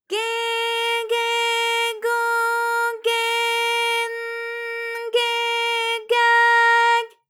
ALYS-DB-001-JPN - First Japanese UTAU vocal library of ALYS.
ge_ge_go_ge_n_ge_ga_g.wav